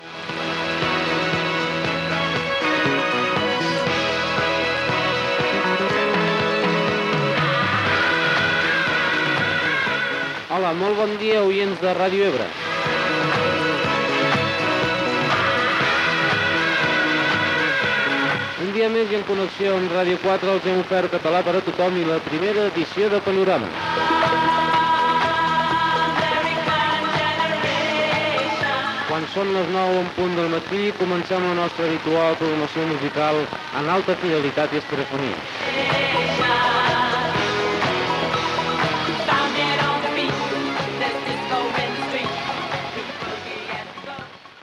Identificació i hora després de la connexió amb la programació de Ràdio 4